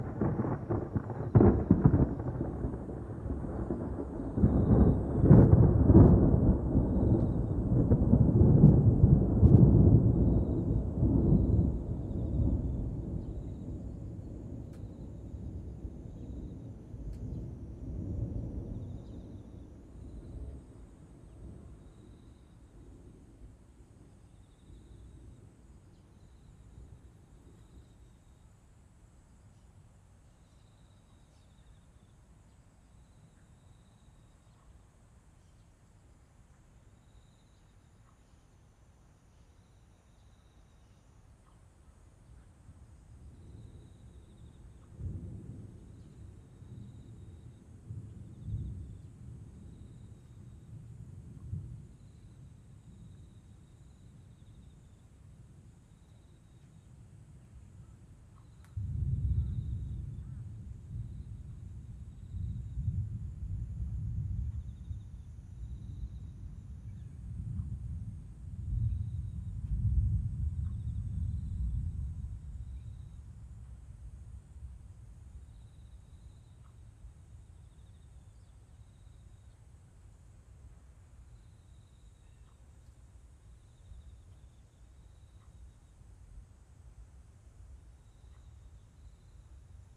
自然の音【素材音源〜雷様と虫の声】